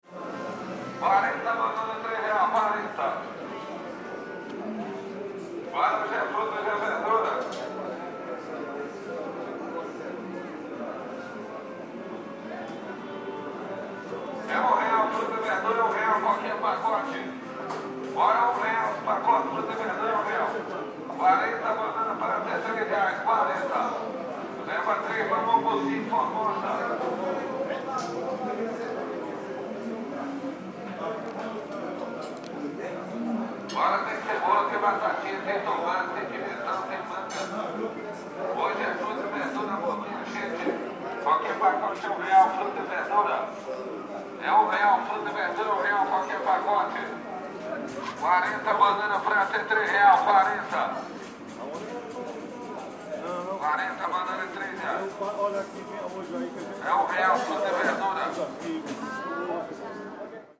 Vendedor de frutas e verduras chama a freguesia. Ali pertinho, em um restaurante, músico começa a esquentar os tamborins. É um típico som ambiente de sábado, quase meio-dia, na Superquadra 303 Norte, em Brasília.
fruits_and_vegetables.mp3